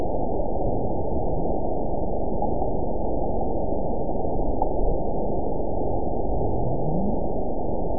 event 912566 date 03/29/22 time 13:31:36 GMT (3 years, 9 months ago) score 9.59 location TSS-AB05 detected by nrw target species NRW annotations +NRW Spectrogram: Frequency (kHz) vs. Time (s) audio not available .wav